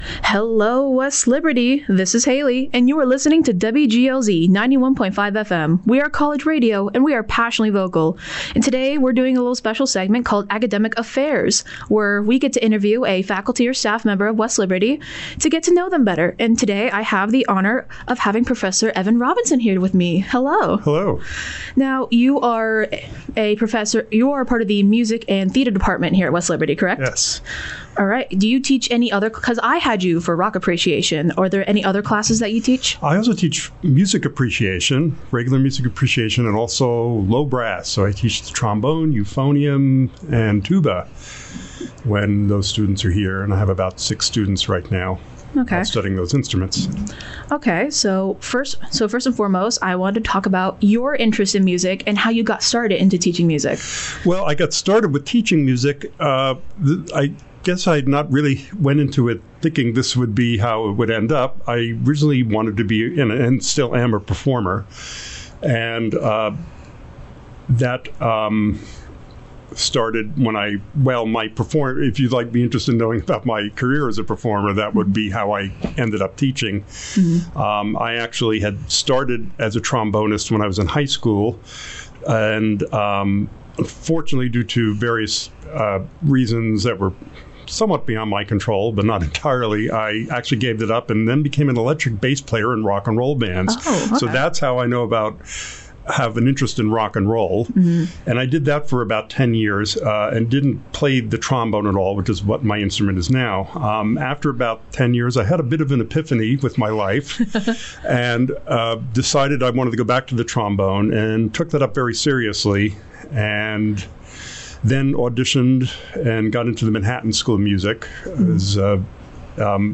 In this “Academic Affairs” interview